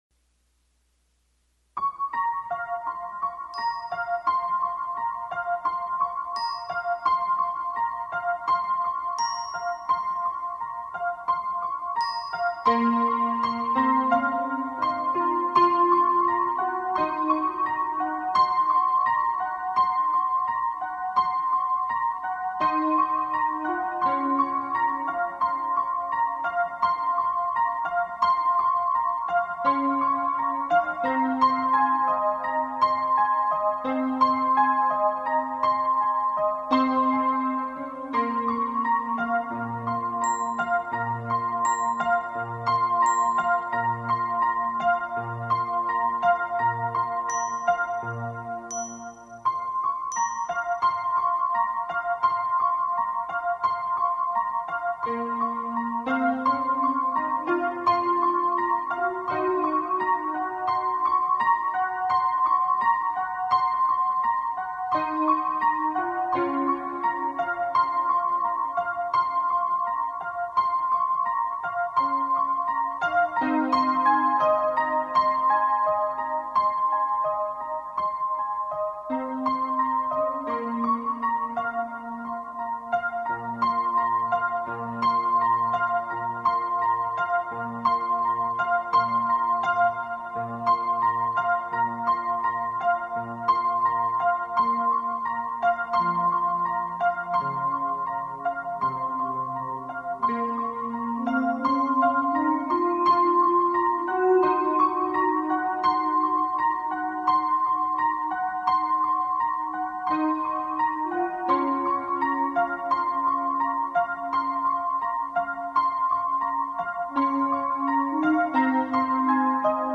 3- Lucy’s Bedroom – Pensive, where we first feel the predatory habits of Dracula on the prowl.
Sounds - Roland Sound Canvass sequenced with Cakewalk Pro, version 3